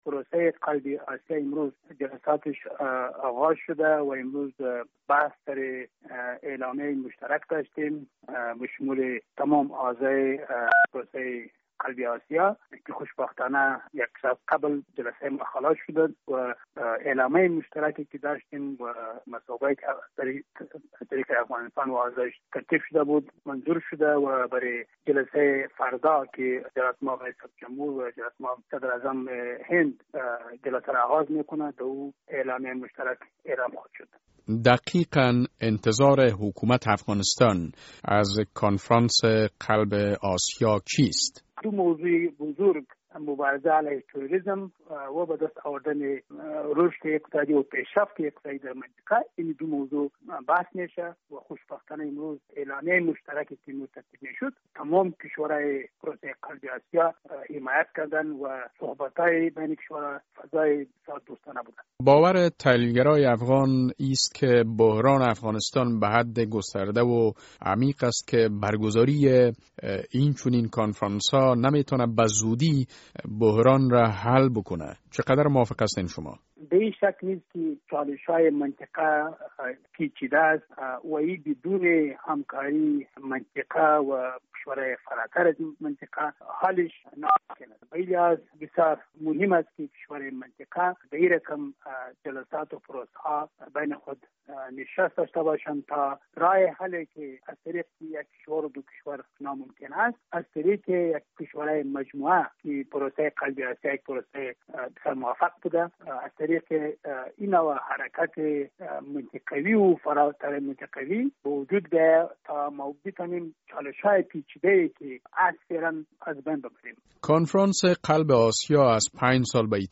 مصاحبه - صدا
شیدا محمد ابدالی، سفیر افغانستان در هند